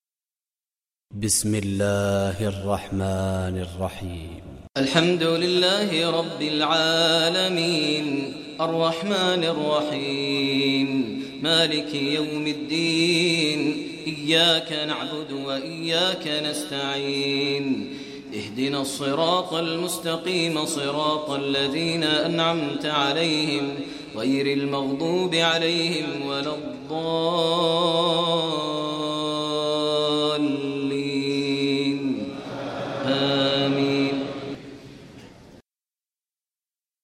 Surah Fatiha Recitation by Maher al Mueaqly
Surah Fatiha, listen online mp3 tilawat / recitation in the voice of Sheikh Maher al Mueaqly.